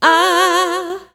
Vcl Shake-F#.wav